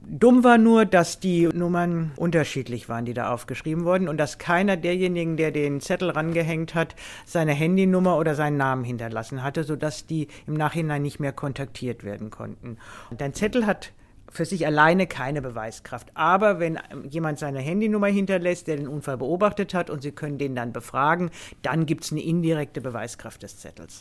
O-Ton: Parkunfall – Zettel von Zeugen müssen Telefonnummer beinhalten